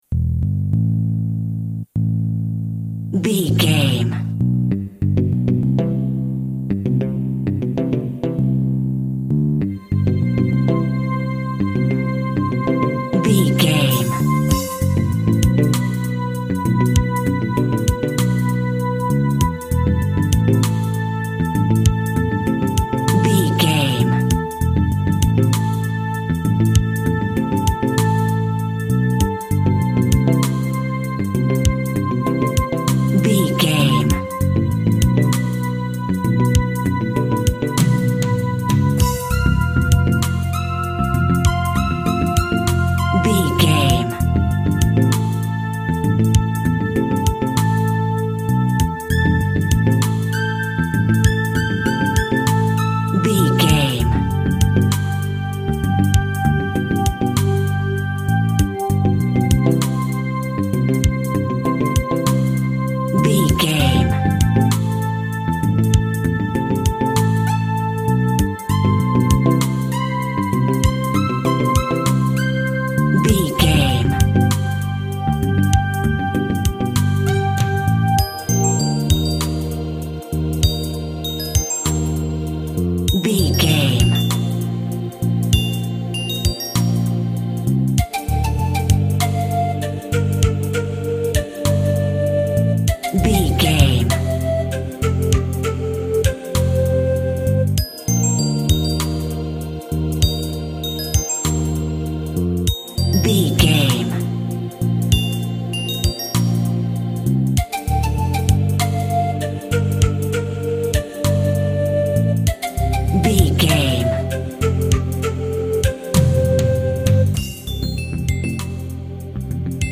80s Pop Ballad.
Aeolian/Minor
B♭
Slow
uplifting
hypnotic
dreamy
peaceful
smooth
groovy
drums
bass guitar
synthesiser
percussion
electronic
synth bass
synth lead